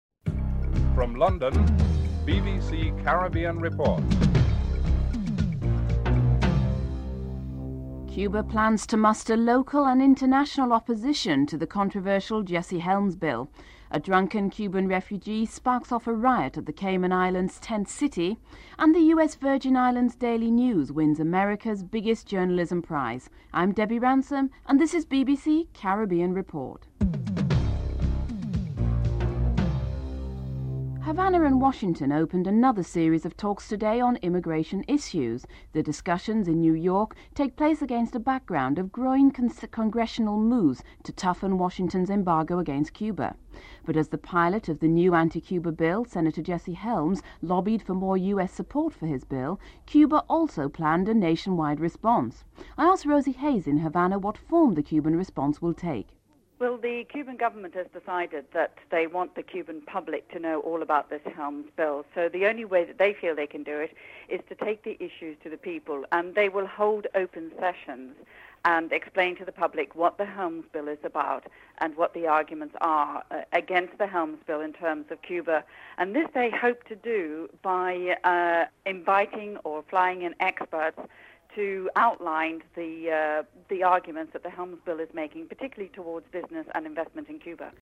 In Jamaica, the Opposition party is threatening to boycott the budget debate.